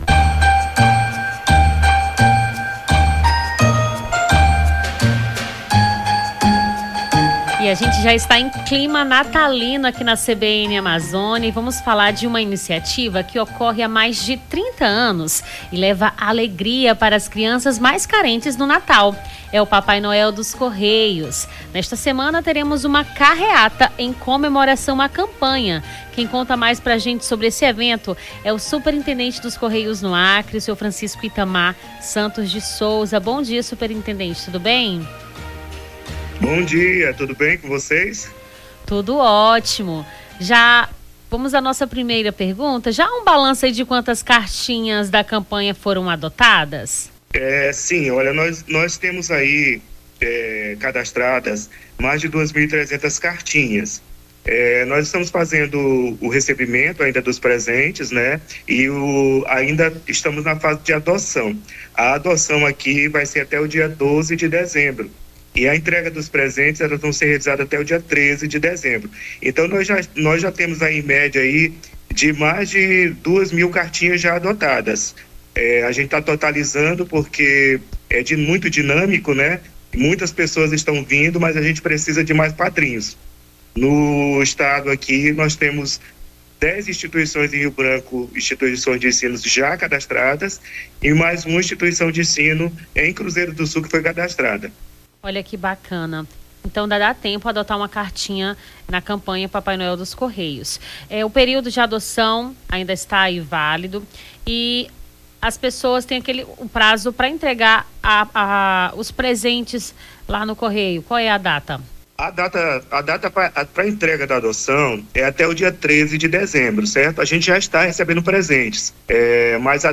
Nome do Artista - CENSURA - ENTREVISTA PAPAI NOEL DOS CORREIOS (27-11-24).mp3